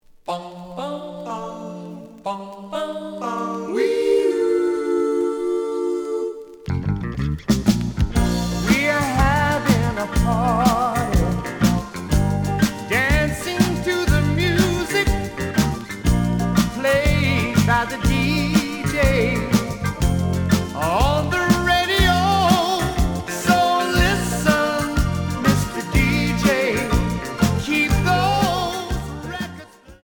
The audio sample is recorded from the actual item.
●Genre: Soul, 80's / 90's Soul